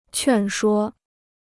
劝说 (quàn shuō): to persuade; persuasion.